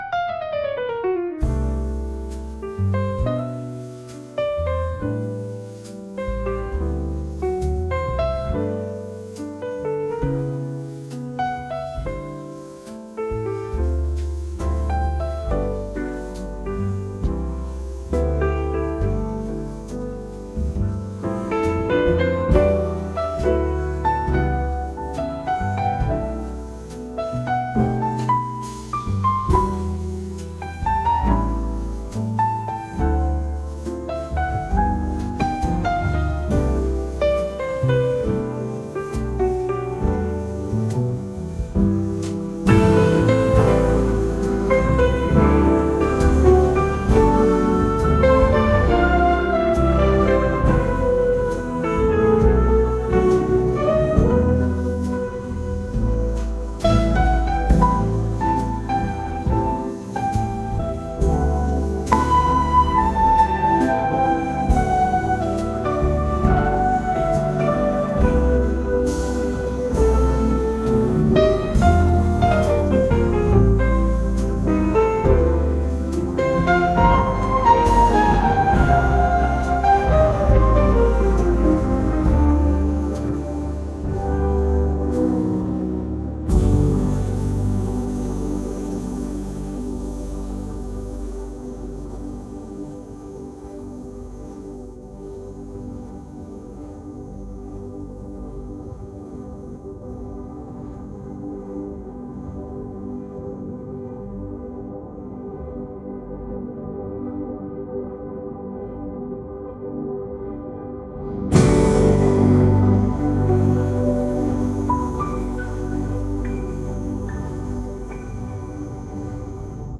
jazz | soul & rnb | lounge